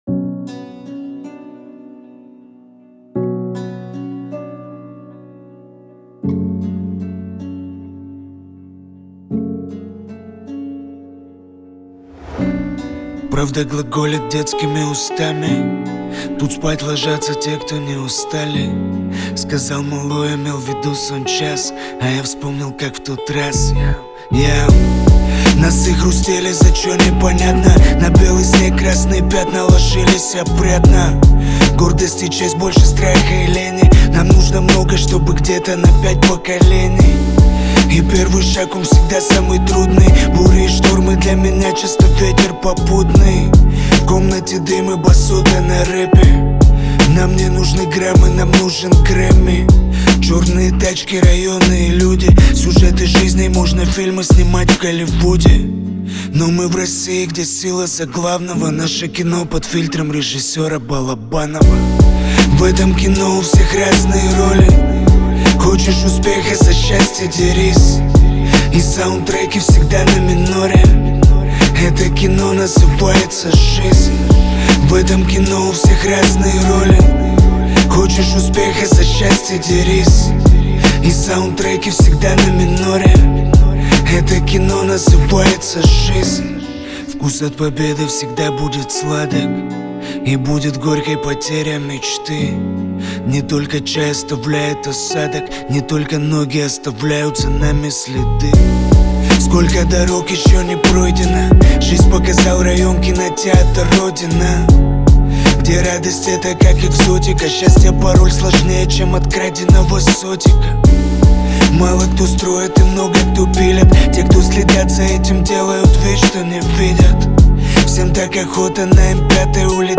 Жанр: Hip-Hop